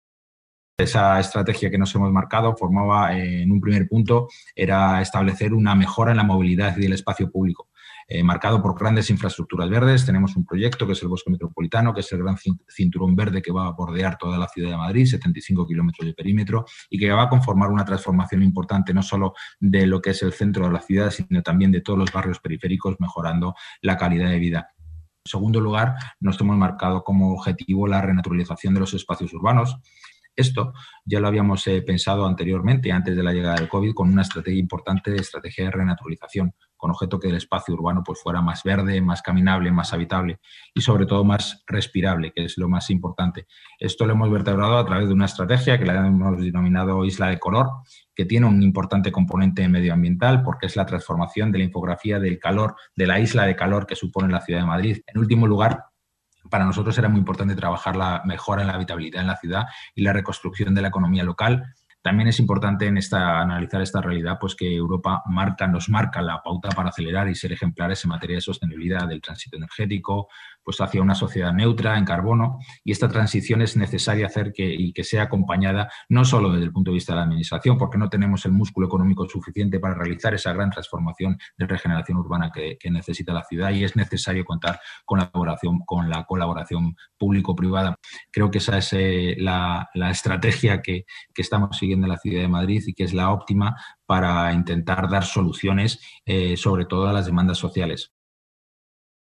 El delegado de Desarrollo Urbano ha participado en el IV Foro de las Ciudades que se celebra en IFEMA